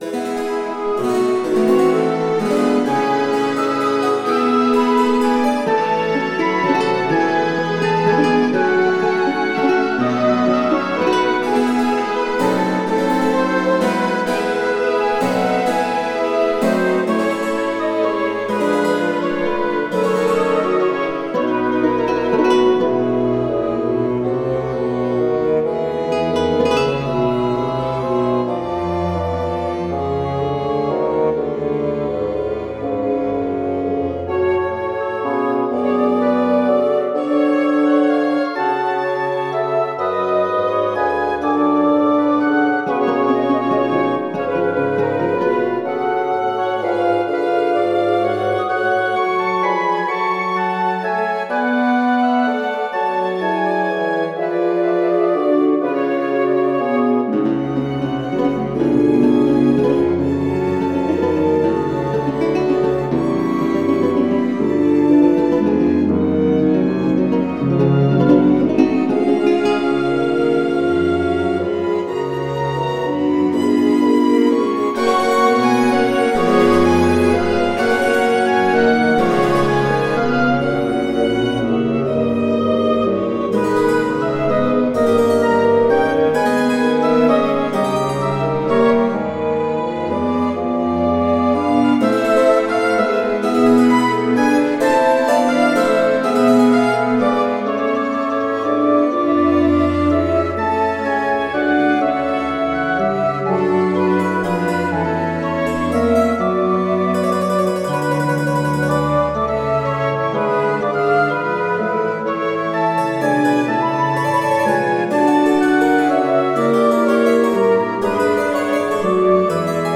It is a Christmas Pastorale played by a small Baroque orchestra with the typical instruments of shepherds, such as flutes, violines, a lute and a „surprise instrument“.